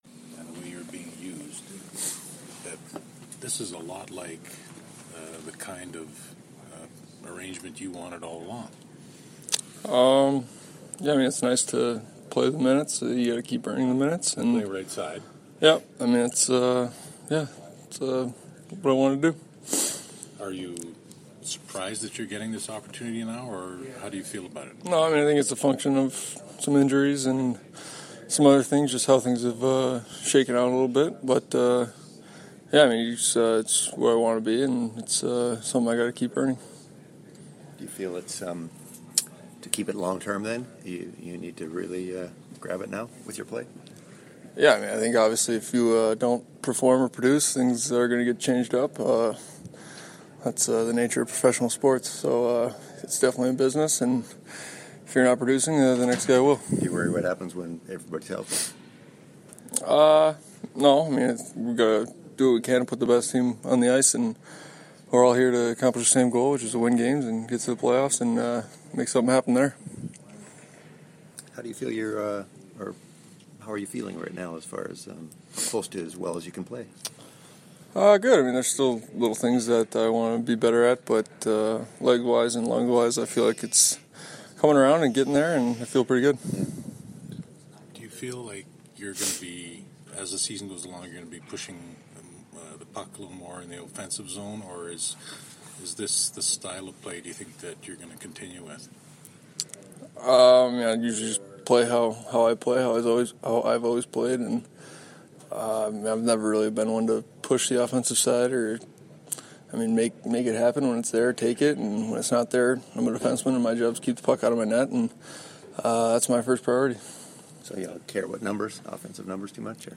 December-7-2016-Jacob-Trouba-scrum.mp3